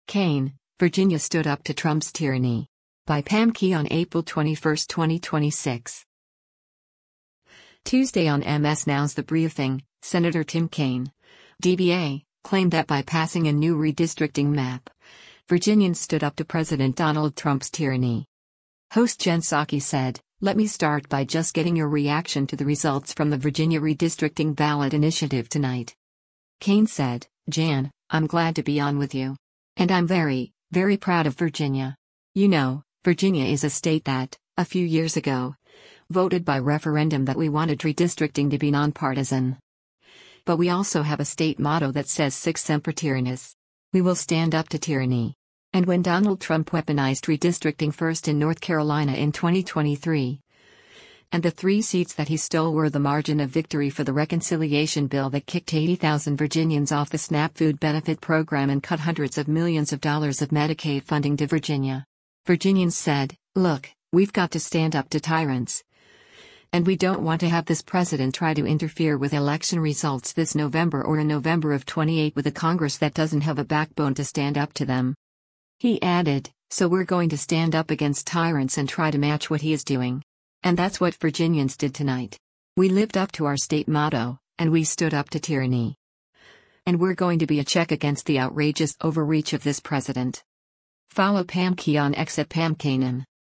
Tuesday on MS NOW’s “The Breifing,” Sen. Tim Kaine (D-VA) claimed that by passing a new redistricting map, Virginians stood up to President Donald Trump’s tyranny.